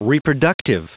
Prononciation audio / Fichier audio de REPRODUCTIVE en anglais
Prononciation du mot : reproductive
reproductive.wav